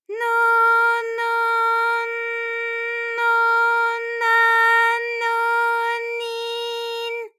ALYS-DB-001-JPN - First Japanese UTAU vocal library of ALYS.
no_no_n_no_na_no_ni_n.wav